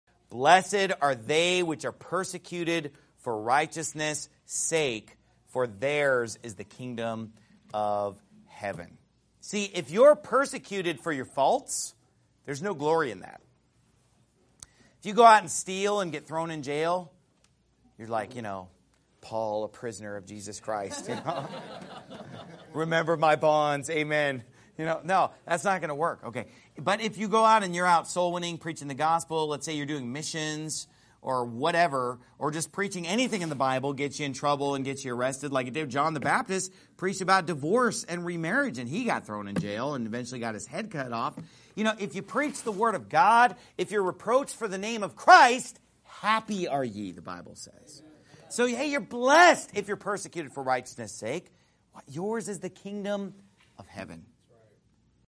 Sermon Clips Pastor Steven Anderson